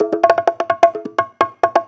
Conga Loop 128 BPM (2).wav